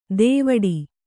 ♪ dēvaḍi